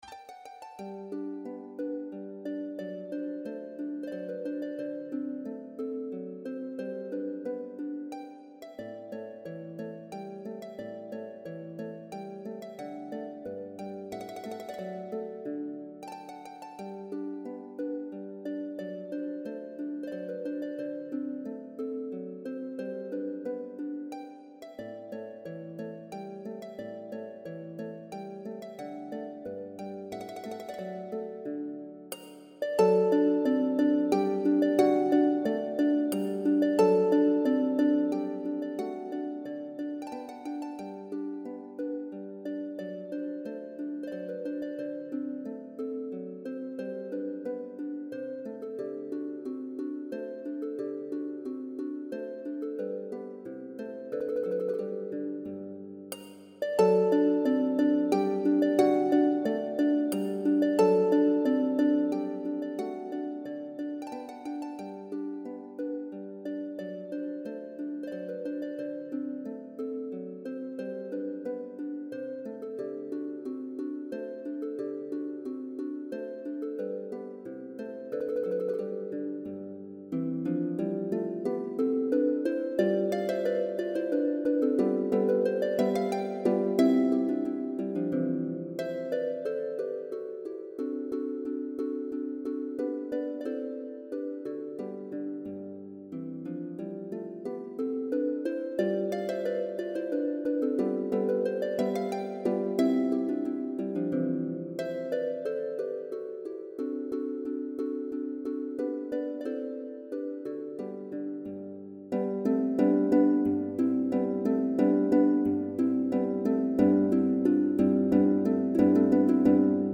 Arrangement for 34-string lever harp (Range C2-D6)